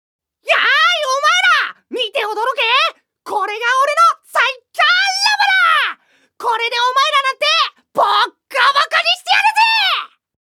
●ボイスサンプル２